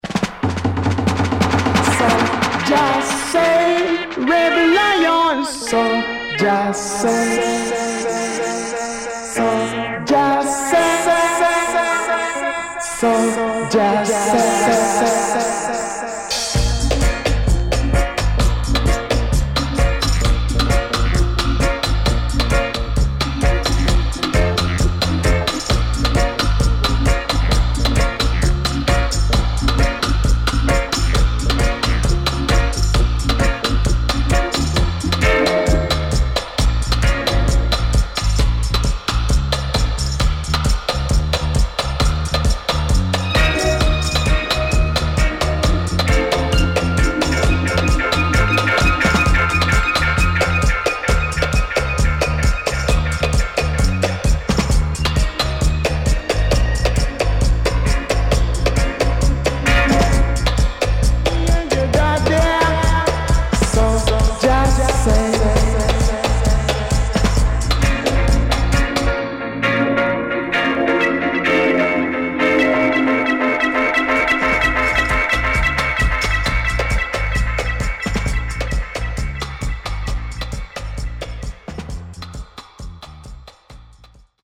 HOME > REISSUE [REGGAE / ROOTS]
Killer Stepper Roots & Great Dubwise.W-Side Good